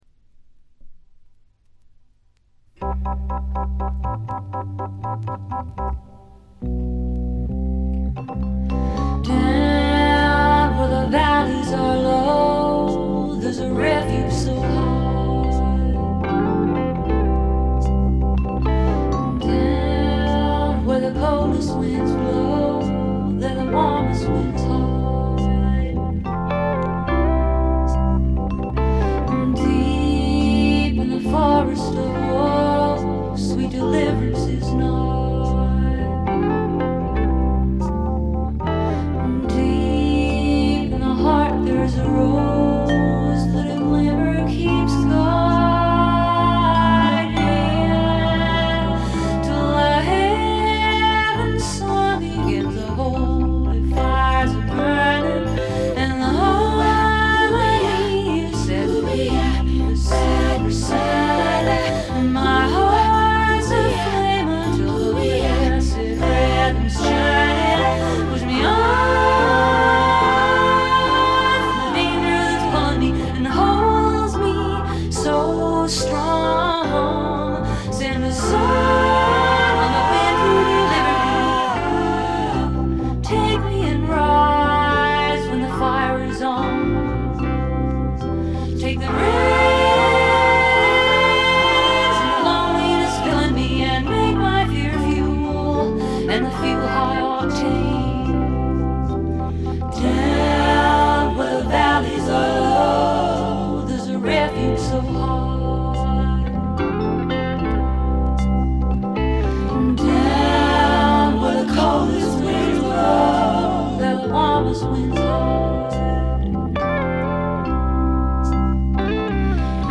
A1終わりからA2冒頭にかけて軽いチリプチ周回気味。これ以外は軽微なチリプチがほんの少し。
試聴曲は現品からの取り込み音源です。